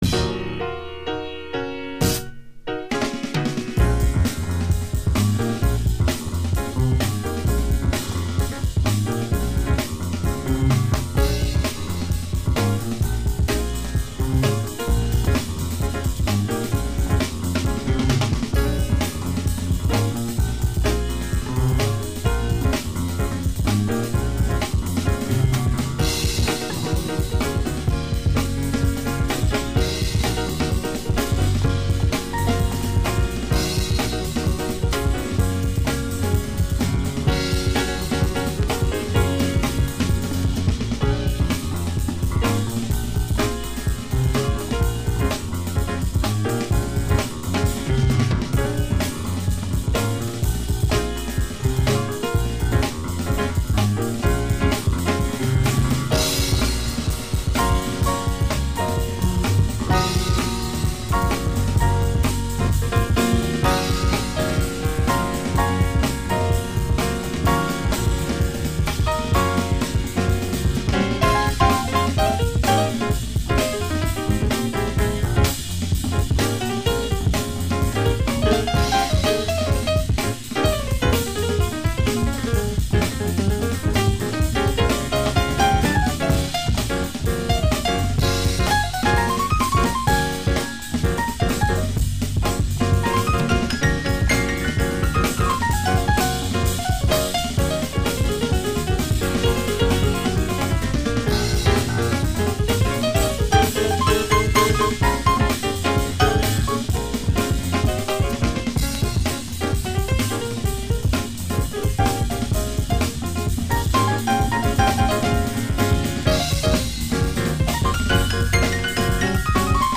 Superb jazz session
German-American trio
Keyboard
bass
drums
a tremendous jazzy breakbeat tune…